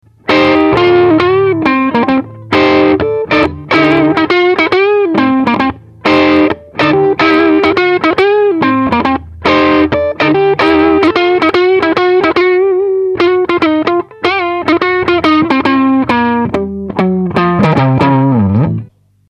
Sesión de grabación de muestras.
. 303KB. Les Paul Custom '68 "Authentic Collection". Pastilla de mástil. Ampli con "FAT"